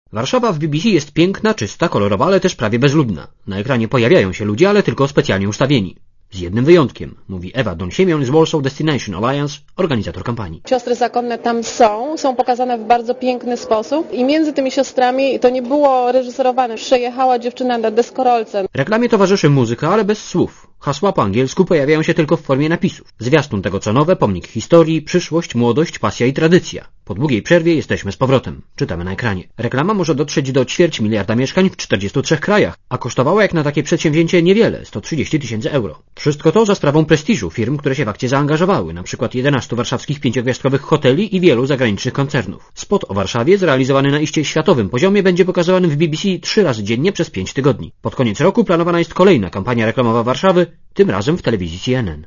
Relacja reportera Radia ZET Całkowity koszt kampanii reklamowej w BBC World to 132 tys. dolarów.